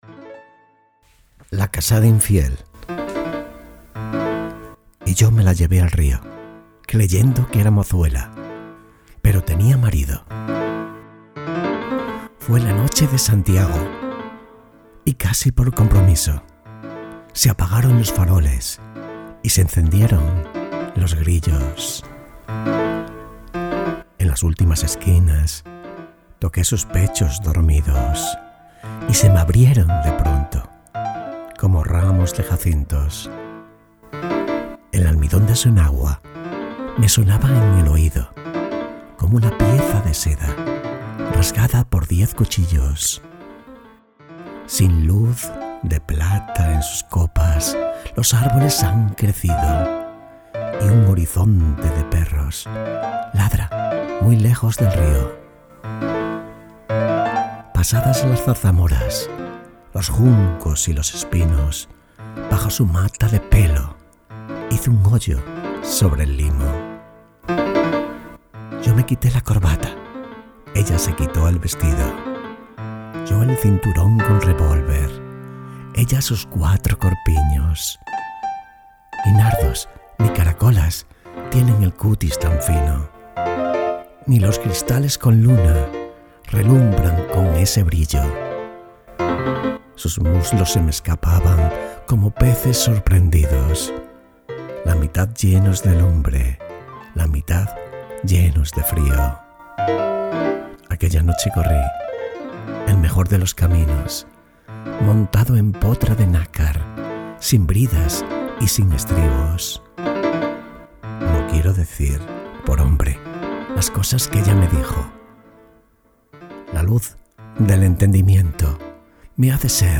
poemas recitados de Lorca, poesias recitadas de Lorca